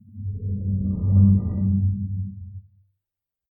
this sound effect when you get the cannon.
cannon-gift.mp3